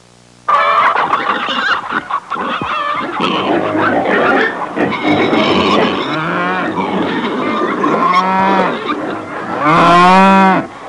Farmhouse From ! Sound Effect
farmhouse-from.mp3